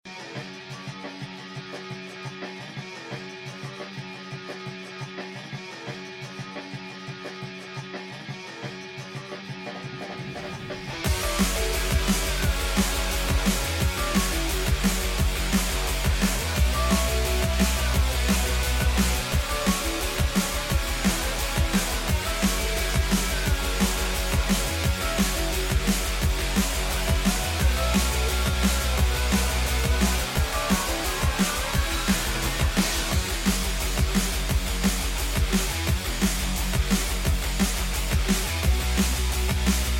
Electronic Rock
драм энд бейс
Жанр: Drum and bass, electronic rock